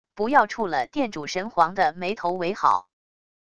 不要触了殿主神皇的霉头为好wav音频生成系统WAV Audio Player